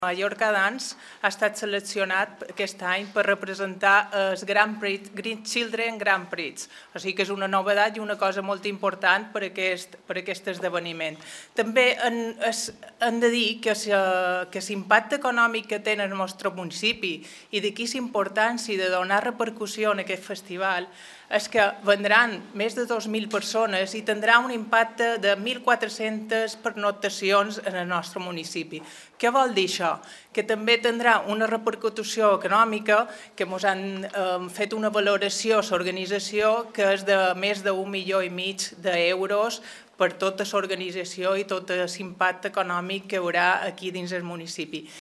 deputy-mayor-for-tourism-elisa-monserrat-statements.mp3